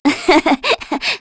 Aselica-Vox_Happy2_kr.wav